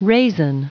Prononciation du mot raisin en anglais (fichier audio)
Prononciation du mot : raisin